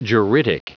Prononciation du mot juridic en anglais (fichier audio)
Prononciation du mot : juridic